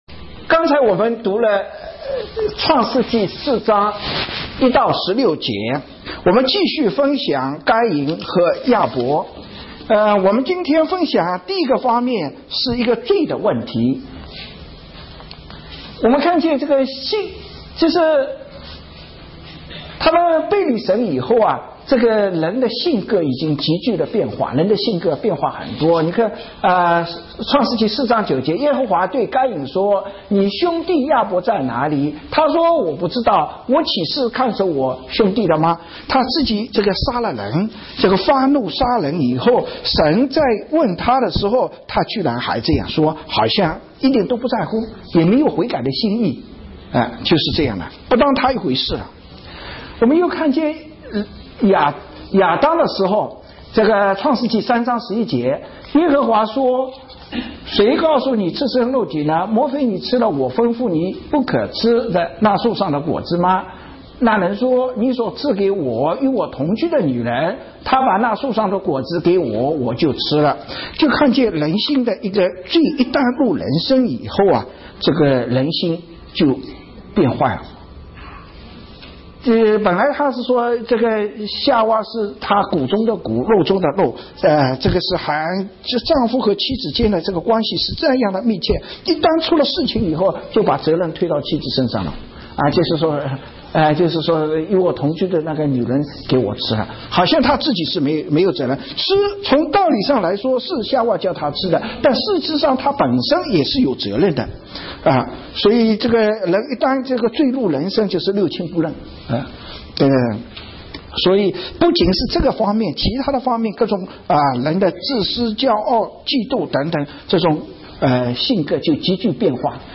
證道